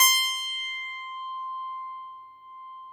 53s-pno17-C4.aif